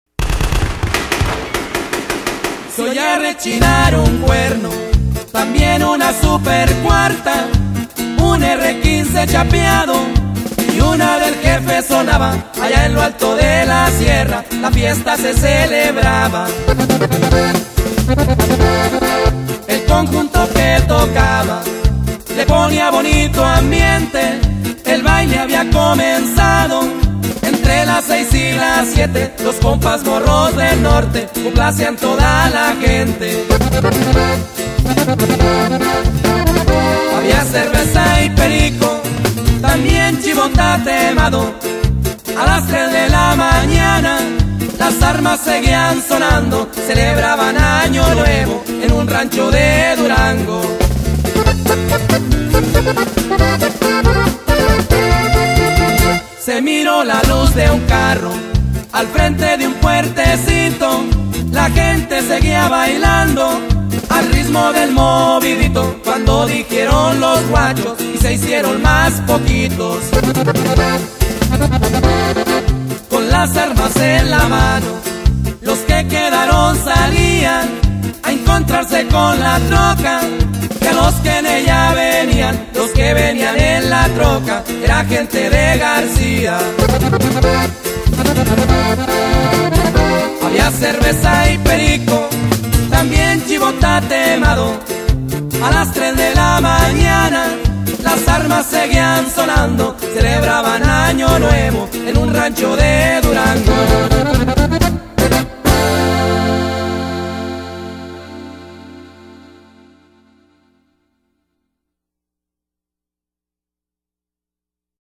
ACORDEON
BAJO SEXTO
BAJO ELECTRICO
BATERIA